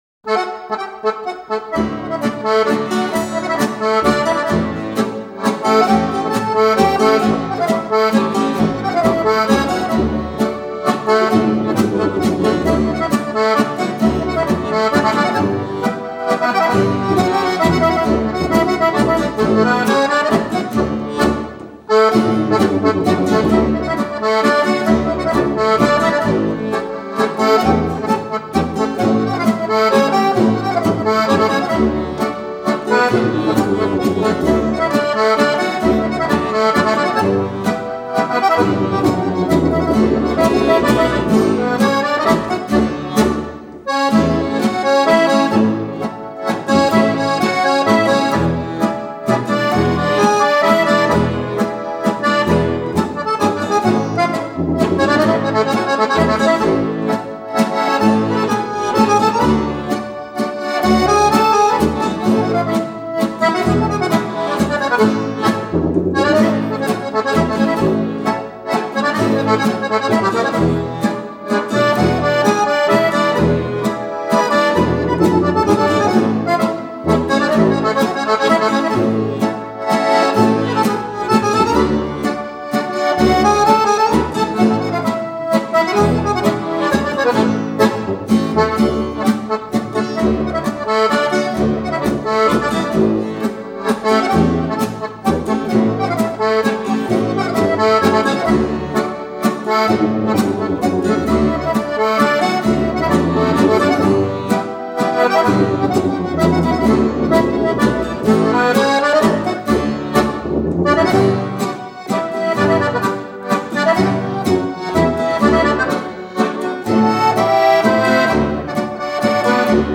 Trio Malcantonese: La cesarina (Mazurka)